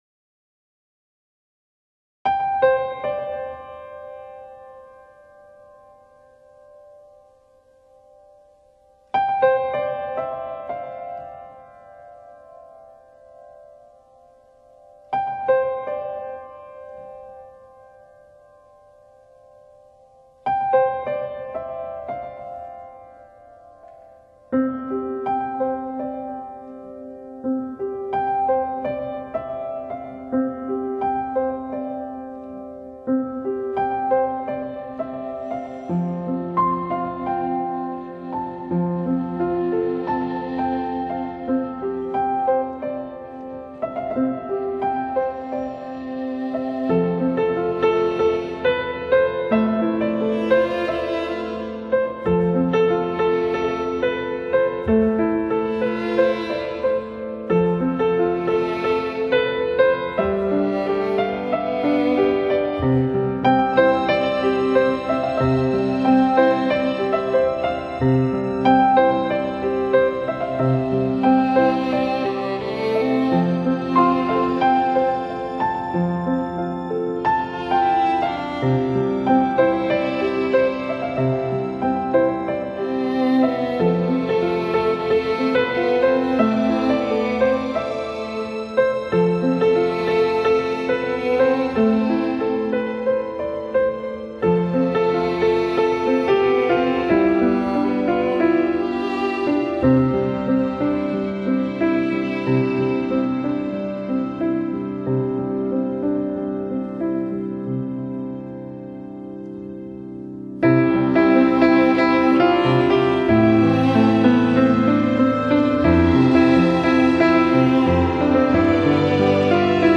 至为深情款款，宁静悠远，就像是一段宁静而舒缓的心灵之旅。
在满满的温暖的旋律、宁静的音符感染下，
是一种女性的细腻与温婉,一种远离忧虑喧嚣的悠雅,